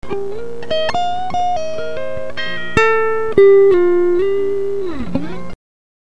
Original unprocessed signal
The original signal peaks at 40957.5, well past the maximum sample value of 32767.